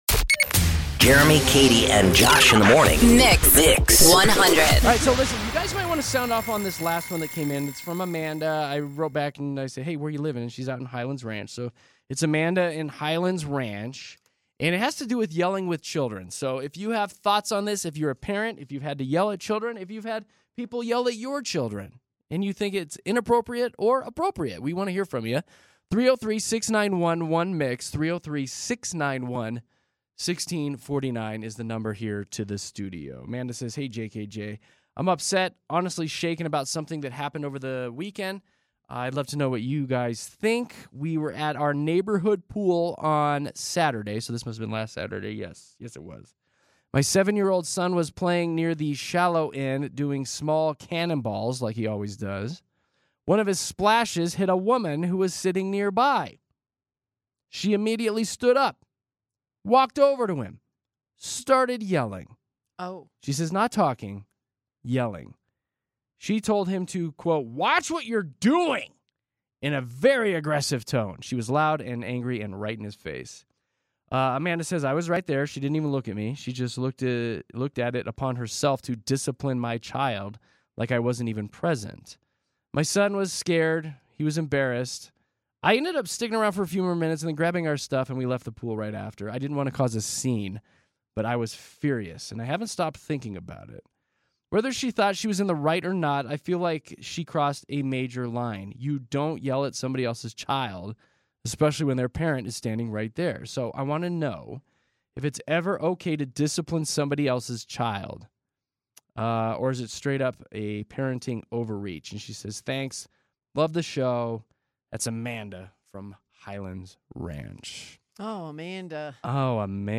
Is it ever acceptable to raise your voice at someone else's children? We invite your calls and comments as we explore if and when you've shouted at kids who aren't yours.